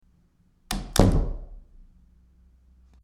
Door Closing 03
Door_closing_03.mp3